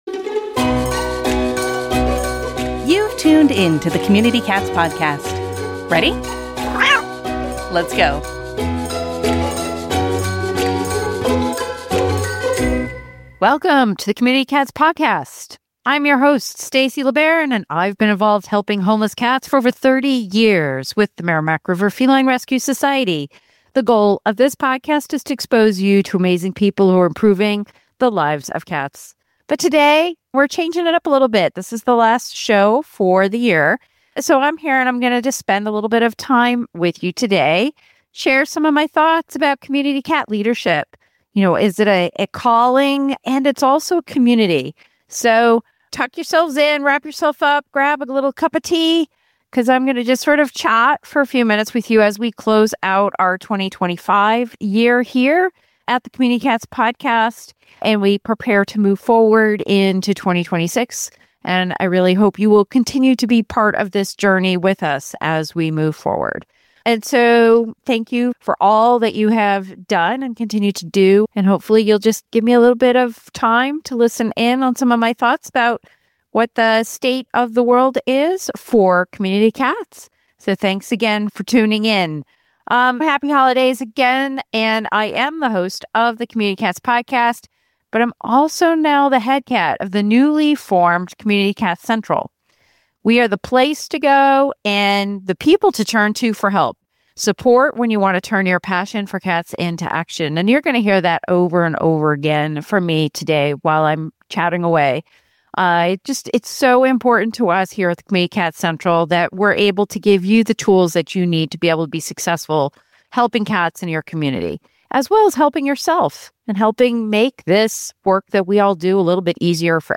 takes the mic solo to share heartfelt reflections, bold vision, and a rallying cry for cat advocates everywhere.